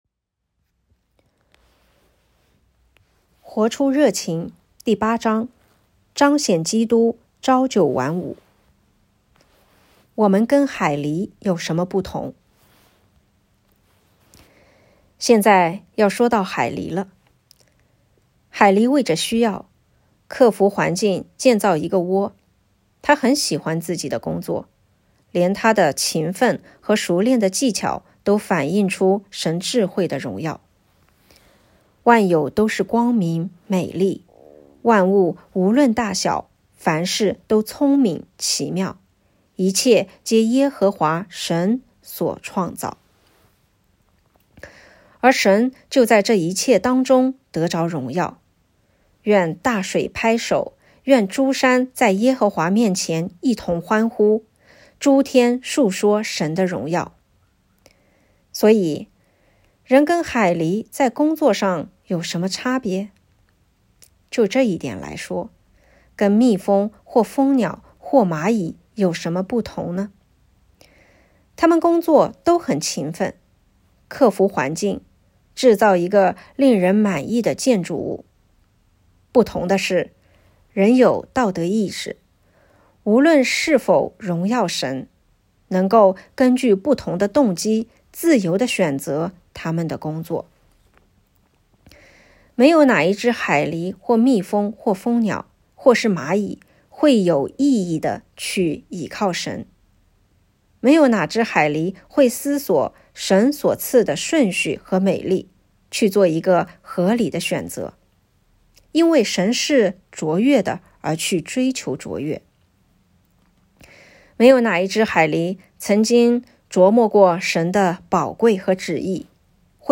2024年7月10日 “伴你读书”，正在为您朗读：《活出热情》 欢迎点击下方音频聆听朗读内容 音频 https